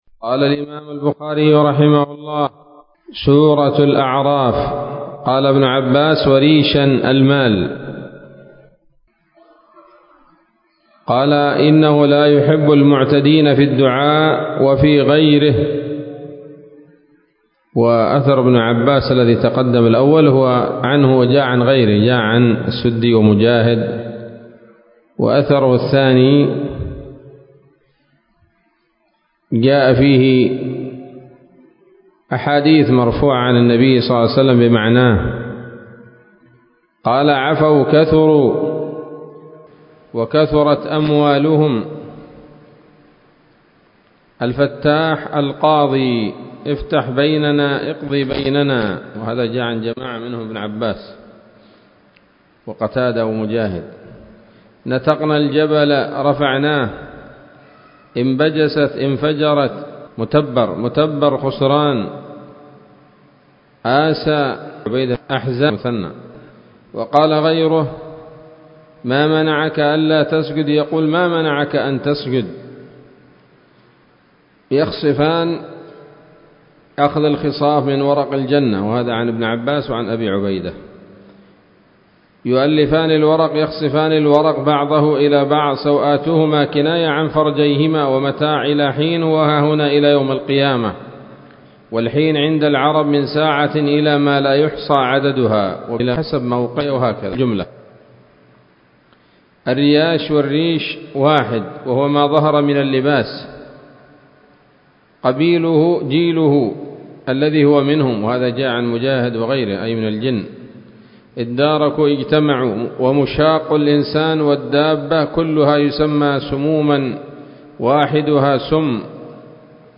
الدرس السادس بعد المائة من كتاب التفسير من صحيح الإمام البخاري